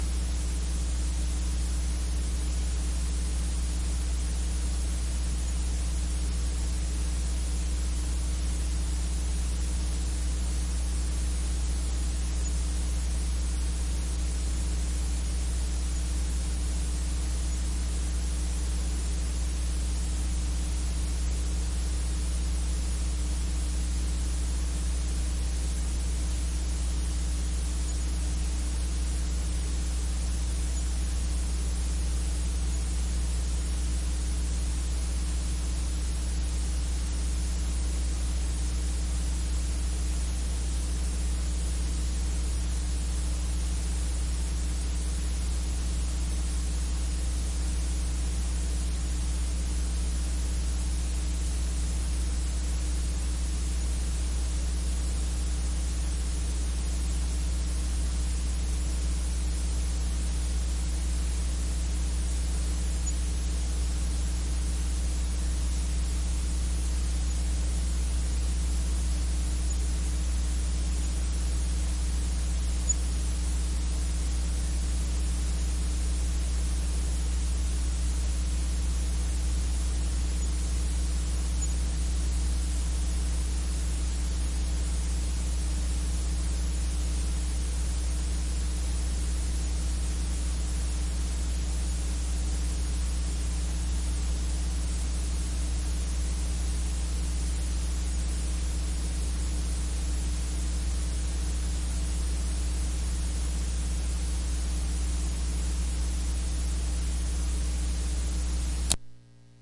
盒式磁带 " 盒式磁带的嘶嘶声和接地的嗡嗡声，没有Dolby NR
描述：盒式磁带嘶嘶声和地面嗡嗡声没有杜比nr.flac
Tag: 嘶嘶声 嗡嗡声 磁带 地面